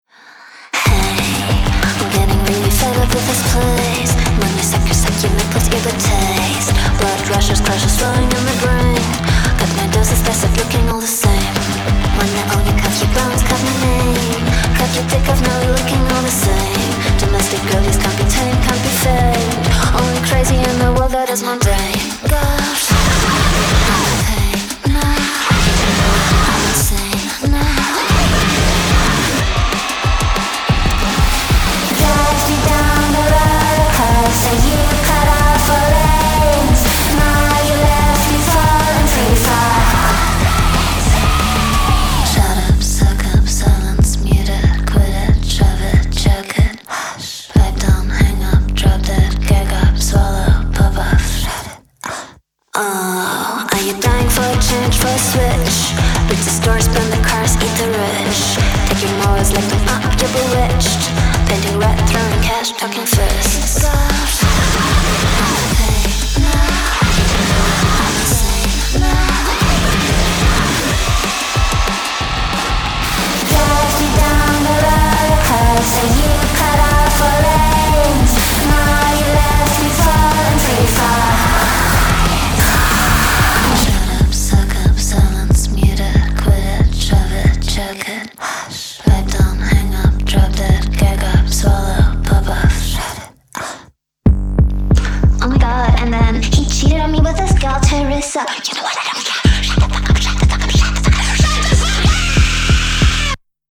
• Жанр: Pop, Rock